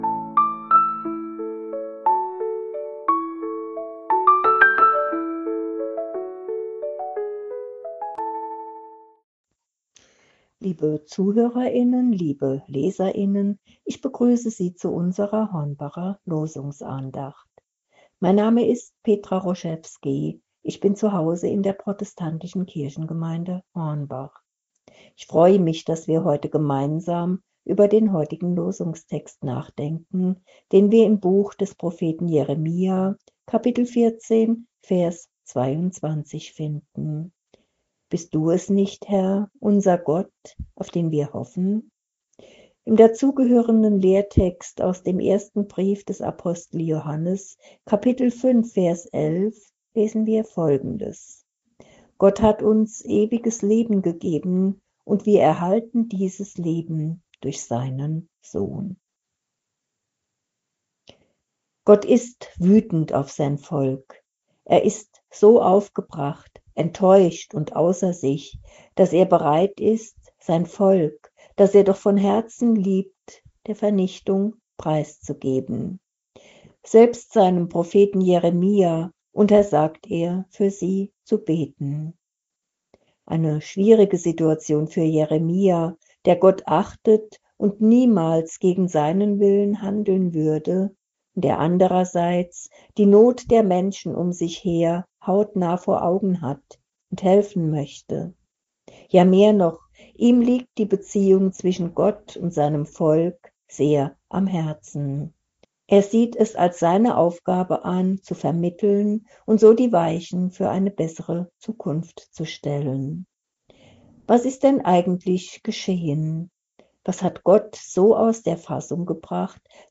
Losungsandacht für Freitag, 01.08.2025 – Prot.